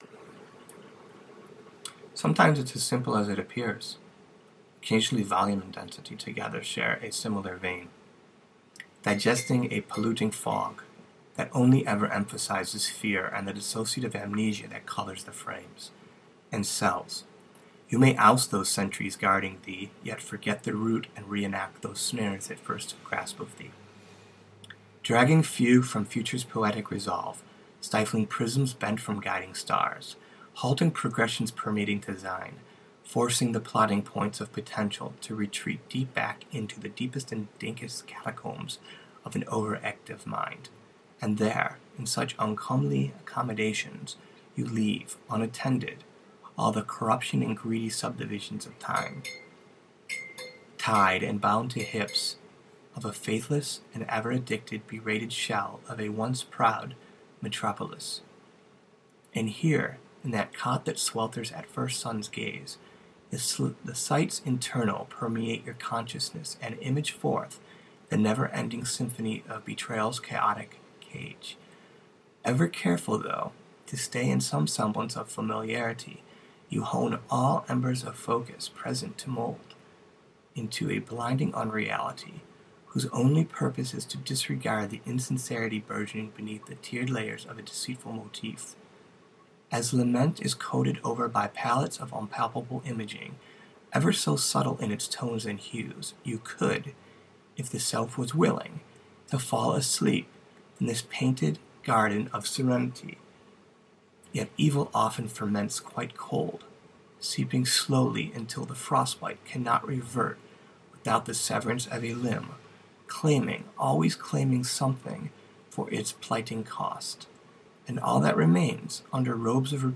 myth, story, saga, quest, prose, prose poetry, recording, reading, metaphor, storytelling